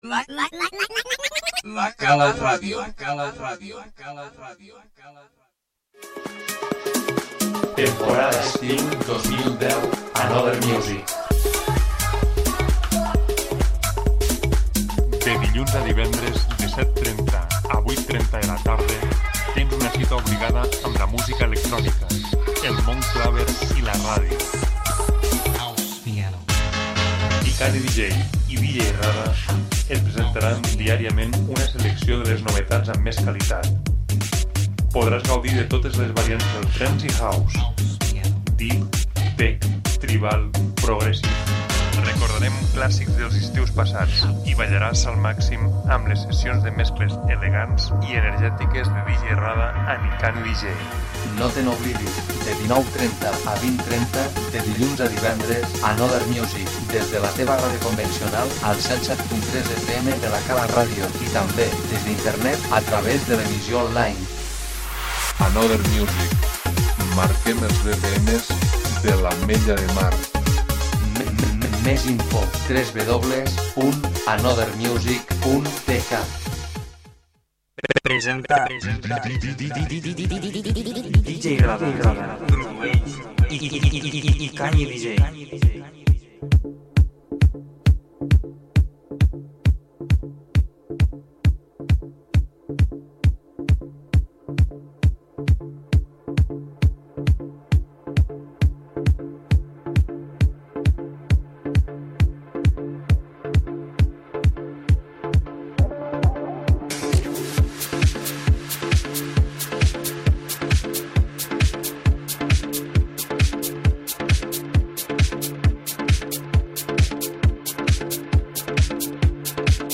House i Trance